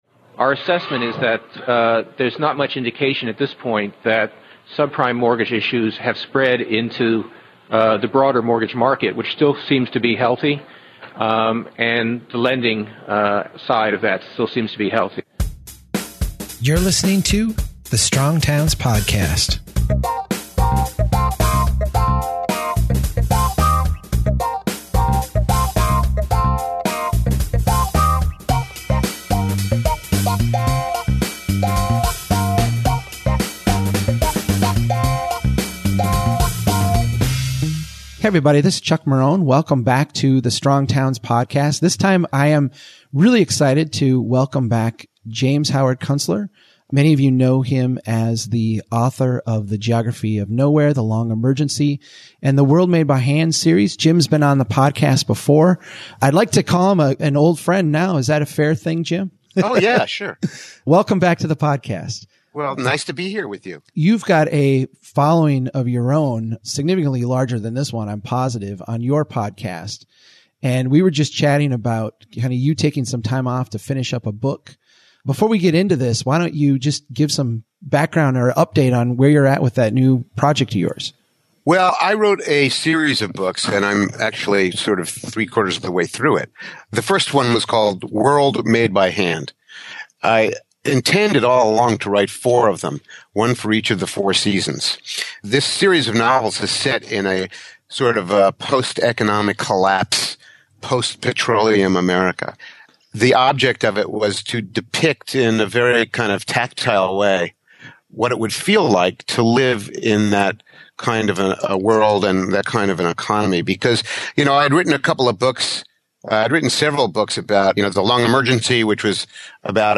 Author and commentator James Howard Kunstler joins the podcast this week to talk about the economy, Federal Reserve interventions and the impact to local communities. Jim also provides an update on his new book, the third installment of the World Made by Hand series.